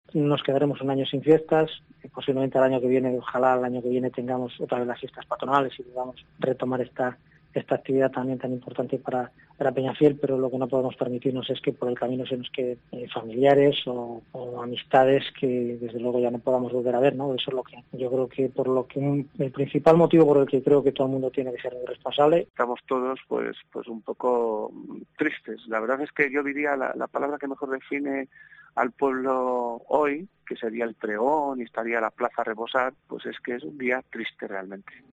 Los alcaldes de Peñafiel y Tudela de Duero que celebrarían las fiestas de San Roque